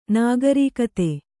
♪ nāgarīkate